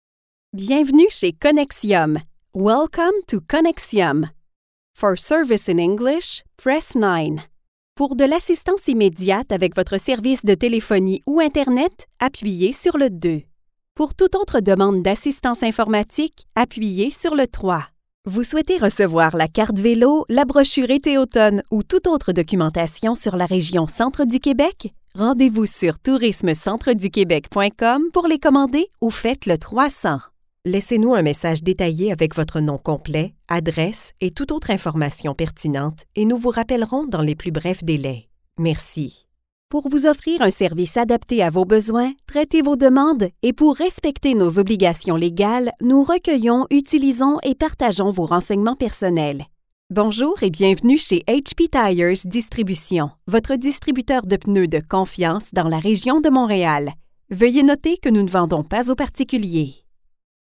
Naturelle, Polyvalente, Amicale, Accessible, Fiable
Téléphonie